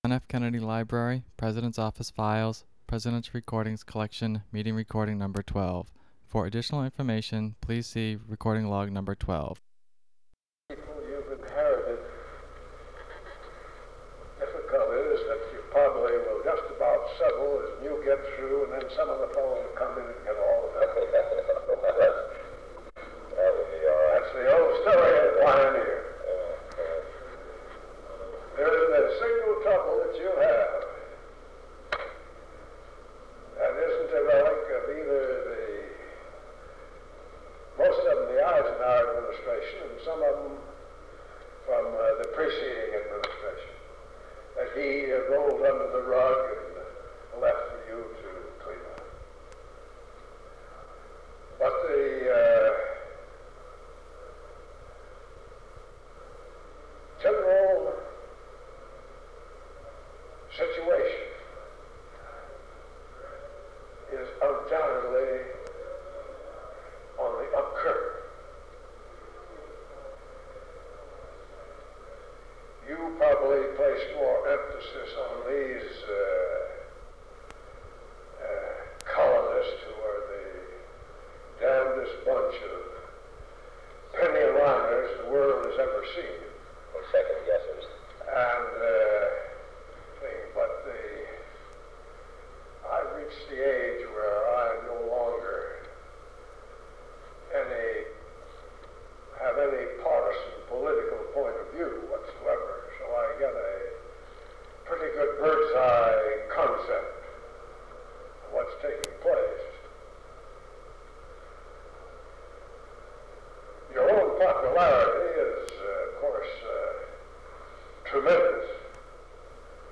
Secret White House Tapes | John F. Kennedy Presidency Meeting with Douglas MacArthur Rewind 10 seconds Play/Pause Fast-forward 10 seconds 0:00 Download audio Previous Meetings: Tape 121/A57.